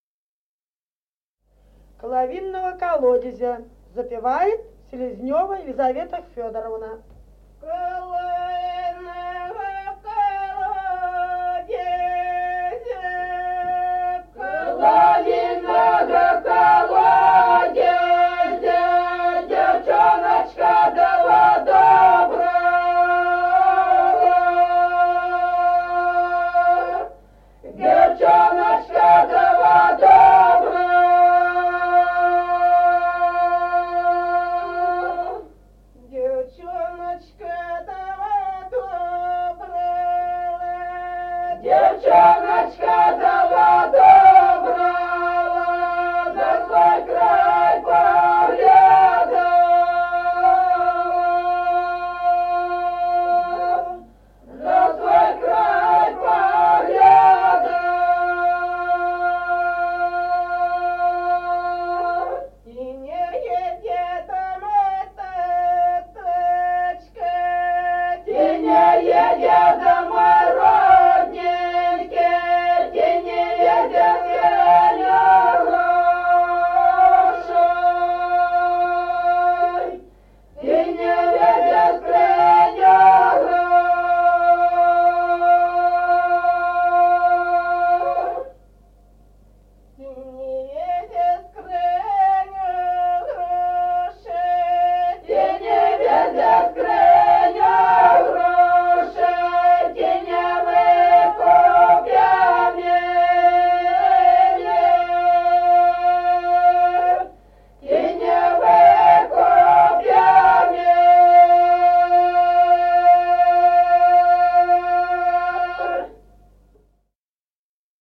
Народные песни Стародубского района «Коло винного колодезя», свадебная, «на придане».
1953 г., с. Остроглядово.